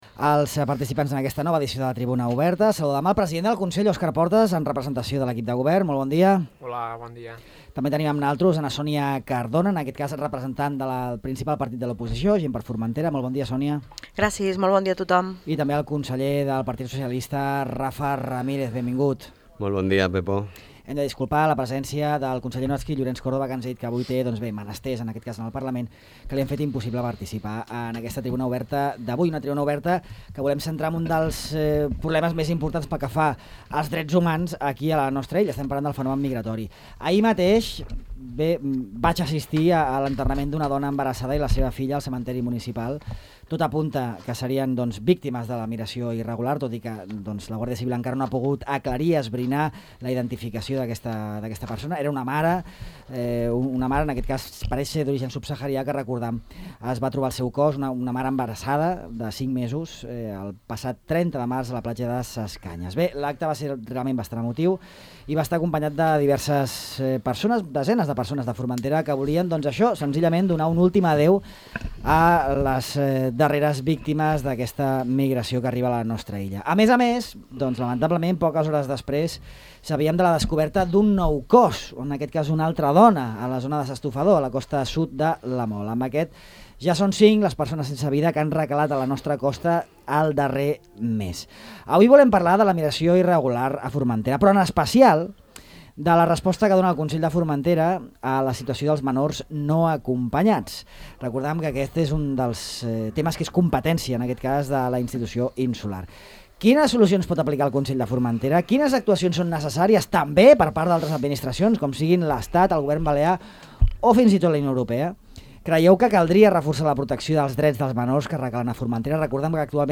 La gestió dels menors migrants, a debat amb Sa Unió, GxF i PSOE
La Tribuna Oberta del mes d’abril ha girat al voltant de la gestió dels menors migrants per part del Consell de Formentera. En el debat hi han participat el president del Consell, Óscar Portas (Sa Unió), Sònia Cardona, en representació de Gent per Formentera, i Rafa Ramírez, conseller dels socialistes de Formentera.